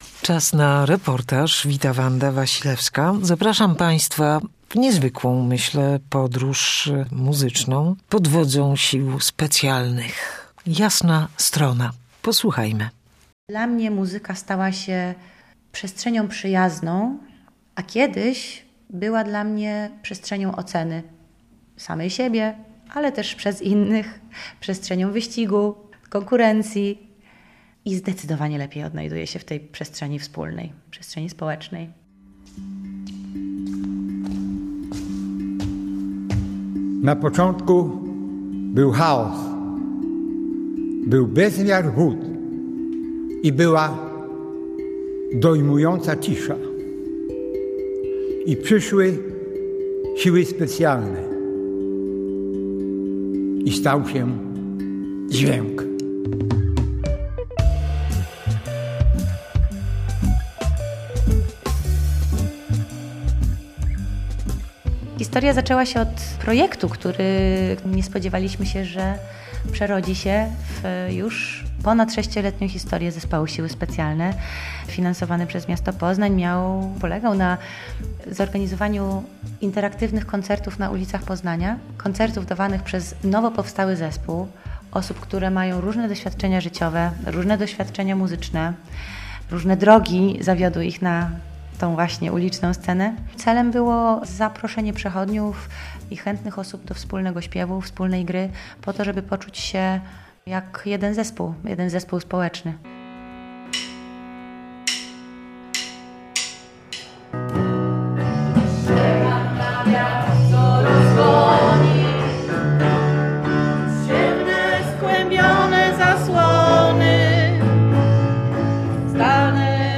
reportażu